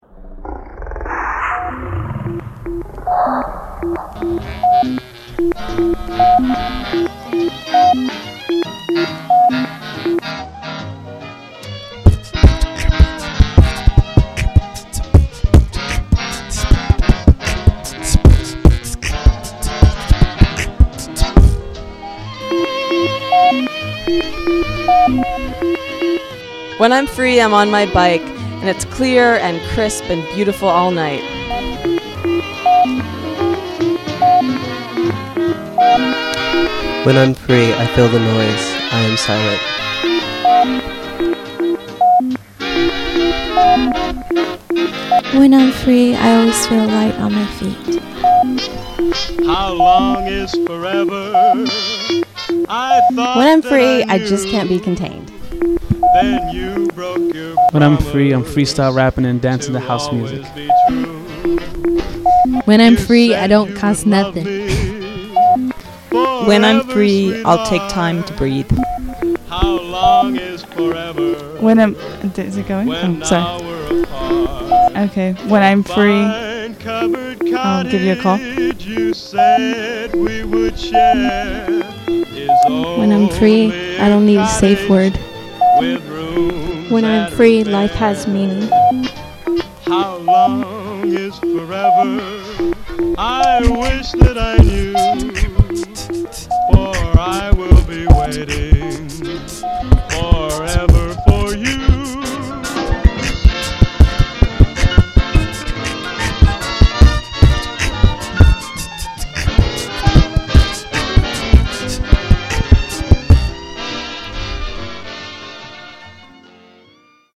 Austin, Oct 08 w/ SkillShare Austin
Grassroots Media Justice Tour
Jazz_growl.mp3